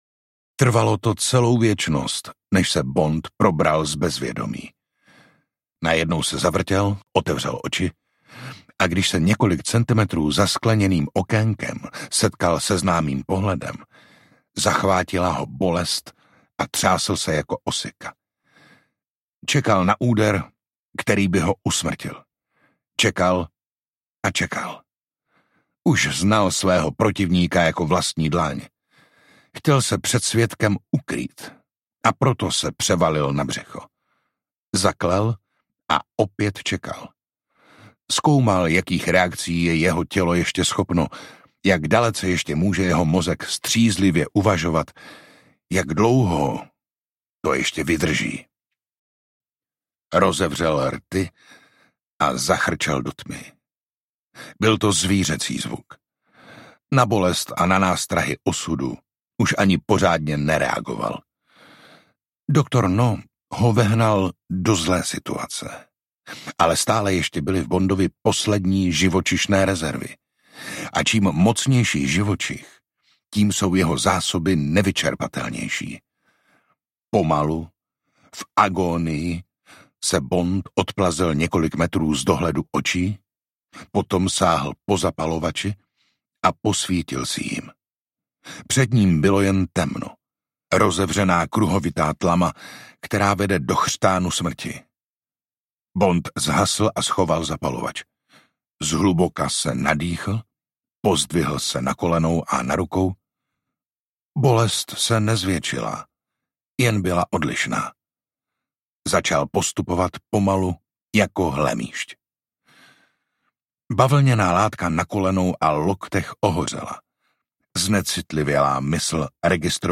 Dr. No audiokniha
Ukázka z knihy
Čte Jiří Dvořák.
Vyrobilo studio Soundguru.